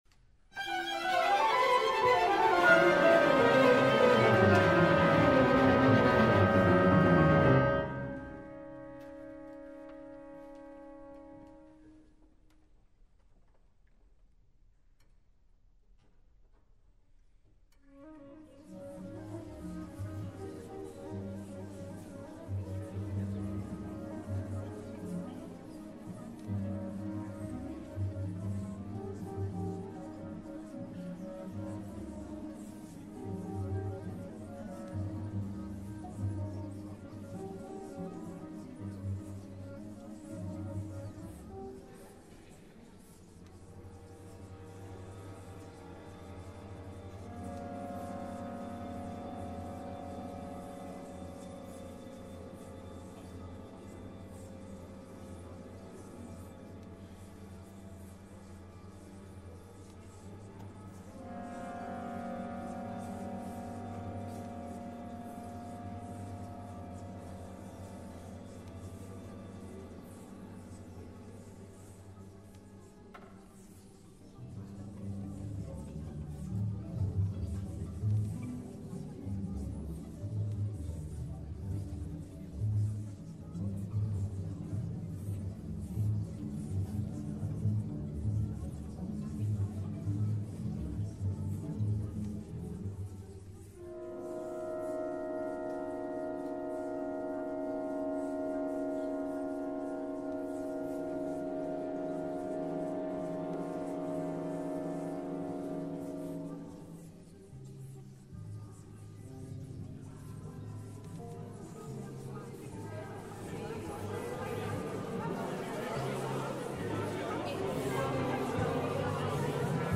3. Orchester, Sprechchor T. 501-510 » hören  » pdf